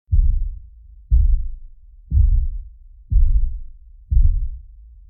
Heavy Walking Footsteps Téléchargement d'Effet Sonore
Heavy Walking Footsteps Bouton sonore